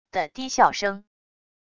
的低笑声wav音频